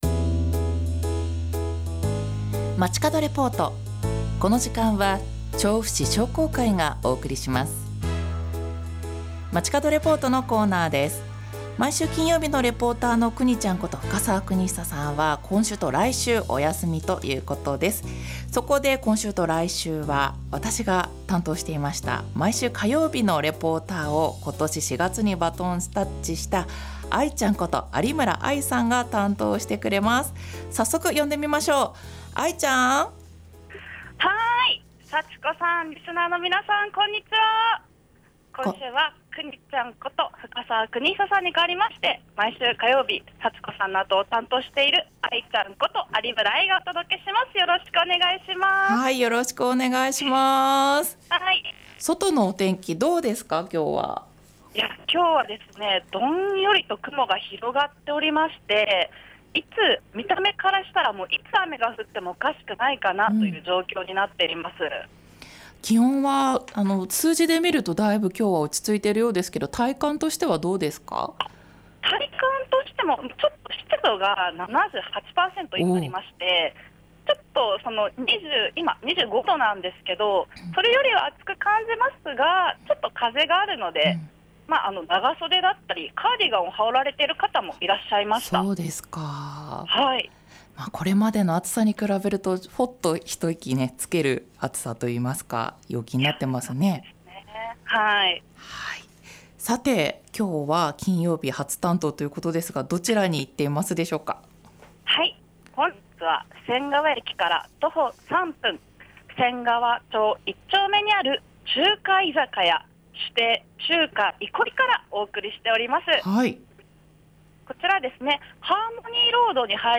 午後のカフェテラス 街角レポート
本日は仙川駅から徒歩3分の場所にある中華居酒屋、「酒亭中華いこい」からお届けしました。